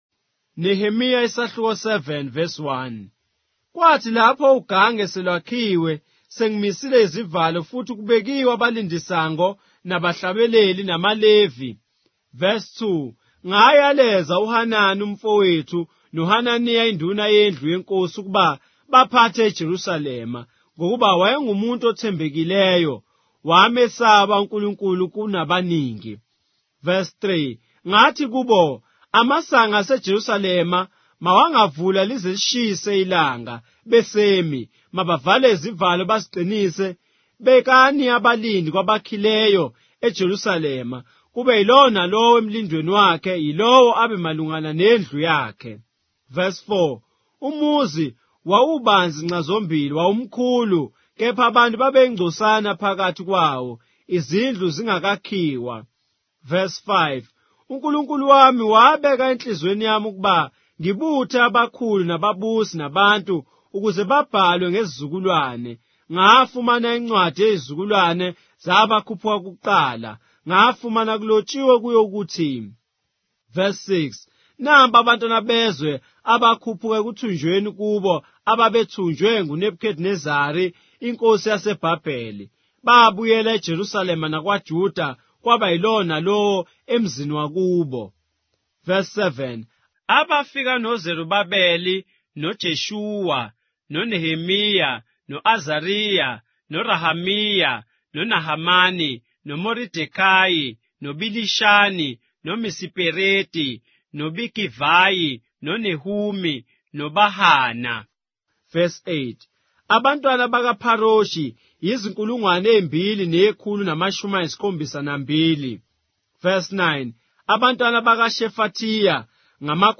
Nehemiah, chapter 7 of the Zulu Bible, with audio narration